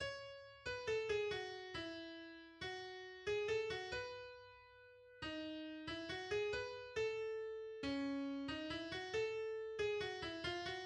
Waltz 1:
The third movement is a waltz,[2] with some unusual elements, for example, hemiola and unbalanced phrase structure at the outset of the movement.